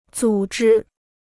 组织 (zǔ zhī): to organize; organization.